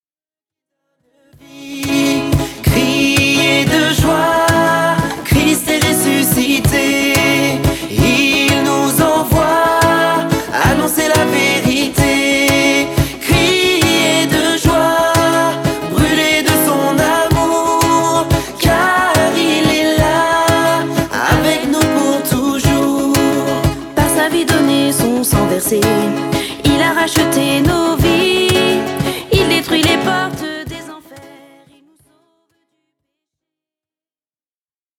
Louange (415)